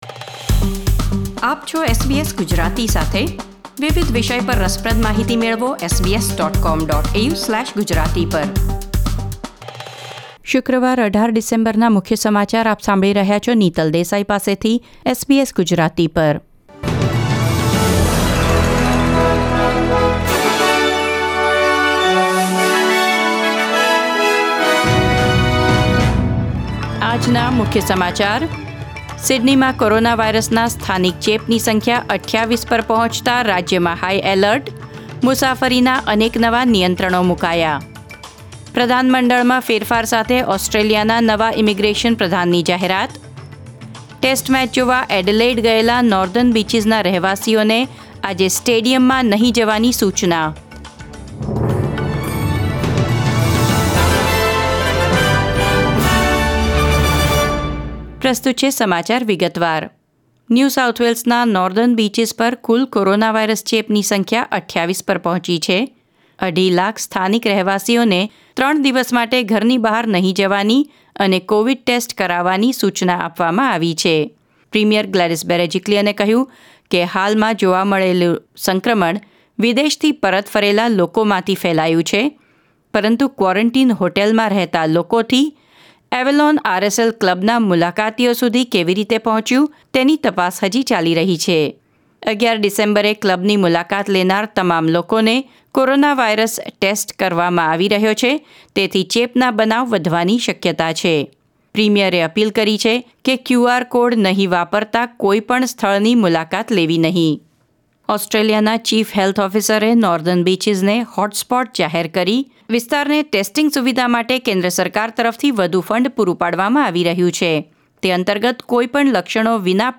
SBS Gujarati News Bulletin 18 December 2020